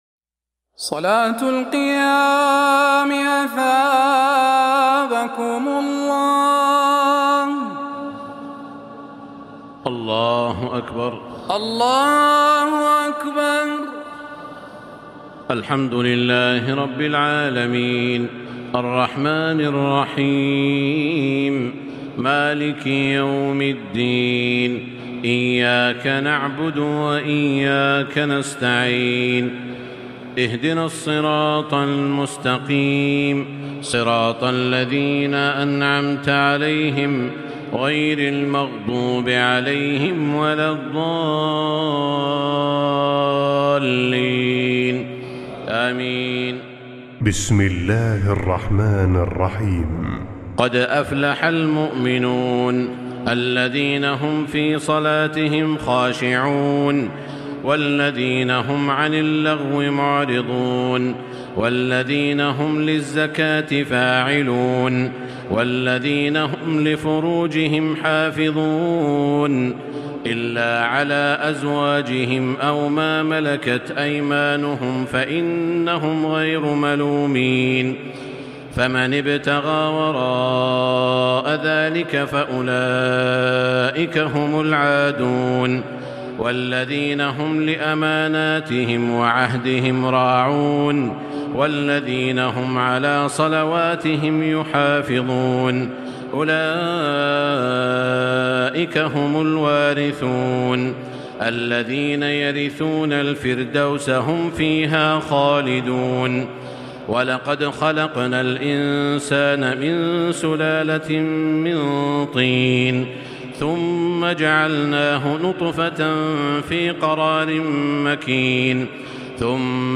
تراويح الليلة السابعة عشر رمضان 1440هـ سورتي المؤمنون و النور (1-20) Taraweeh 17 st night Ramadan 1440H from Surah Al-Muminoon and An-Noor > تراويح الحرم المكي عام 1440 🕋 > التراويح - تلاوات الحرمين